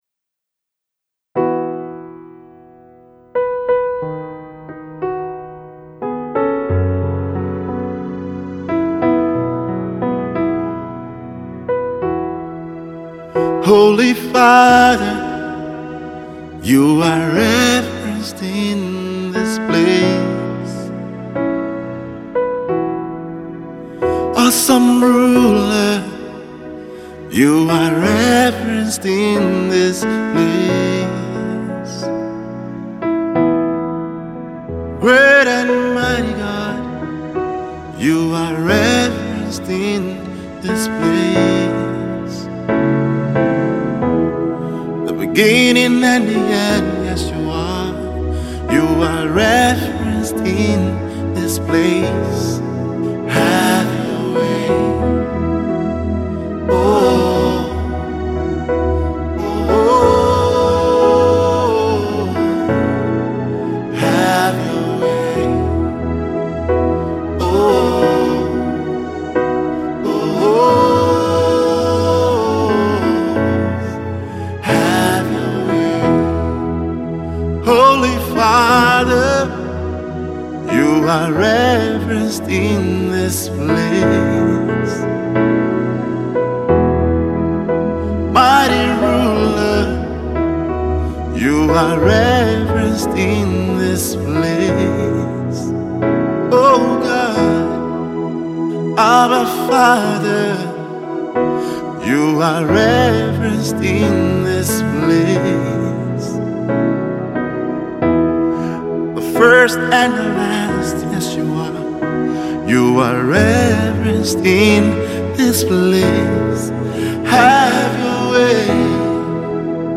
a simple worship tool for everyone yearning for God